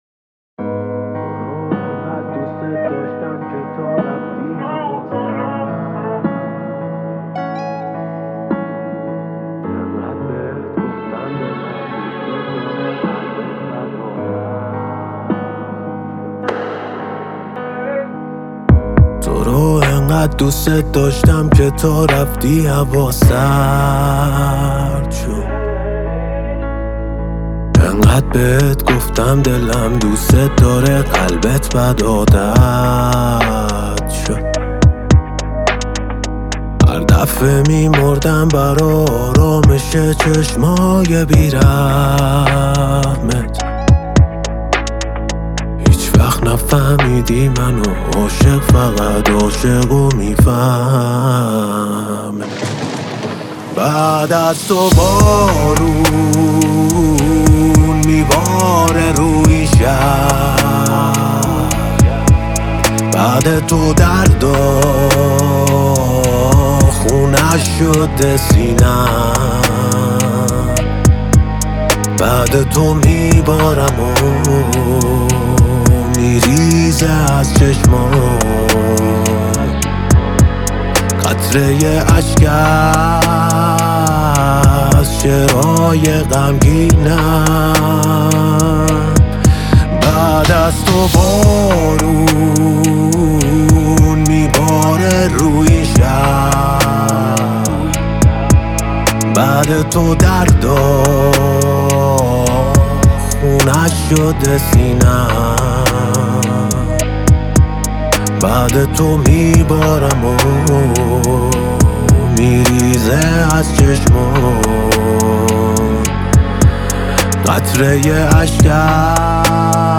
رپ فارس